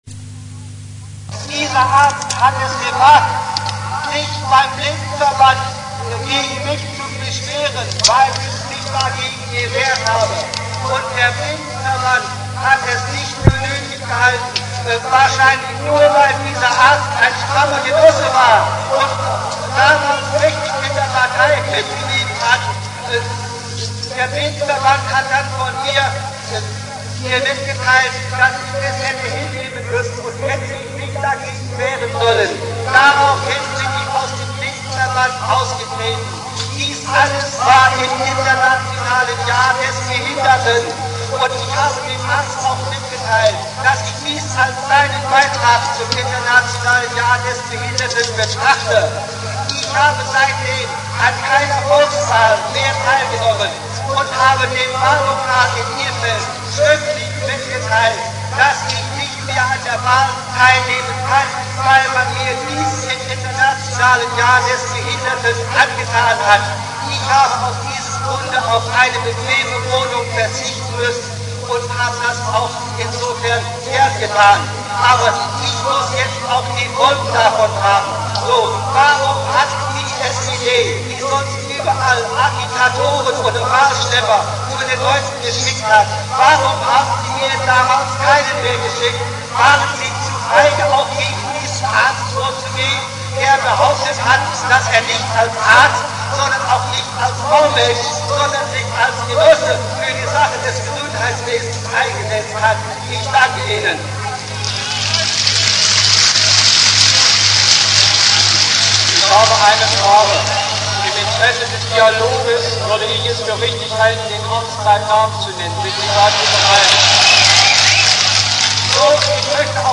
Die nnz veröffentlicht den Mitschnitt dieser Dienstagsdemo... Anzeige Refinery (lang) Im zweiten Teil des historischen Mittschnitts stehen die Diskussionsbeiträge verschiedener Redner im Mittelpunkt. Neben der geforderten Reisefreiheit oder Pressefreiheit geht es aber auch um die Bananen in der SED-Kreisleitung, die es da immer zu kaufen gegeben haben soll.